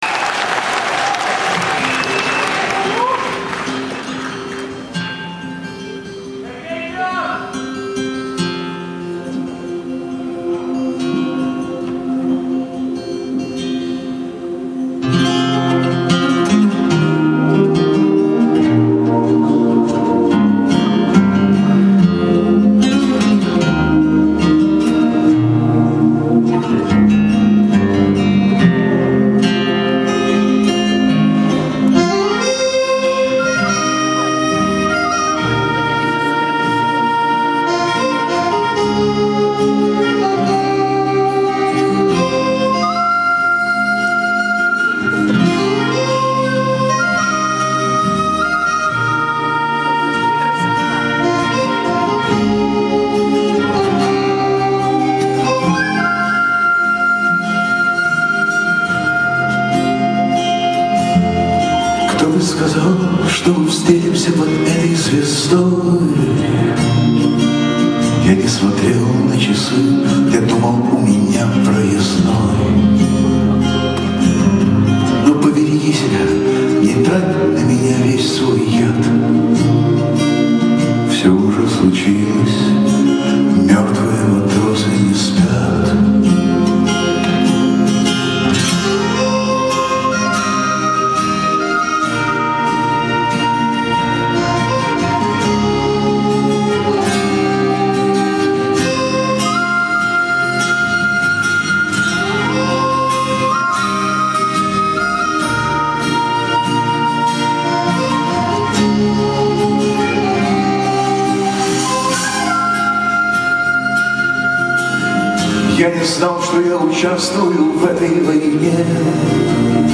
4. 2004-BKZ_Oktyabrskiy